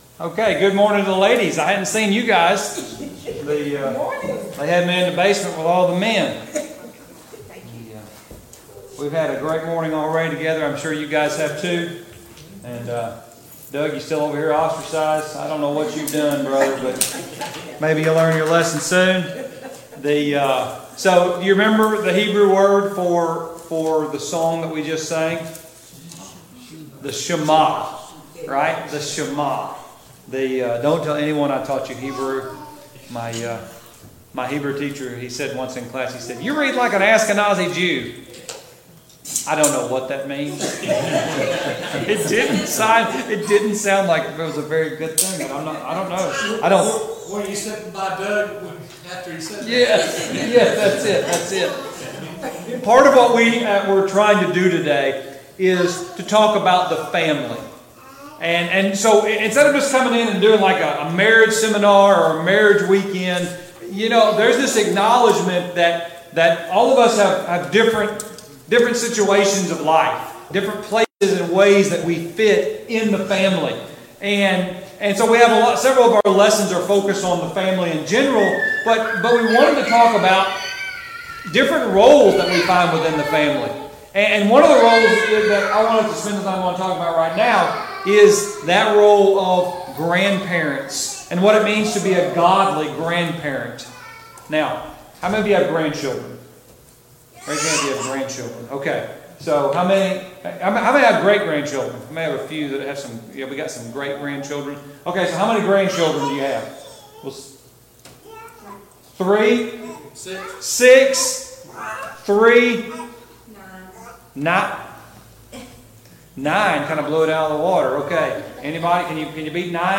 Marriage and Family Training Service Type: Gospel Meeting Topics